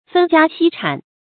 分家析產 注音： ㄈㄣ ㄐㄧㄚ ㄒㄧ ㄔㄢˇ 讀音讀法： 意思解釋： 見「分煙析產」。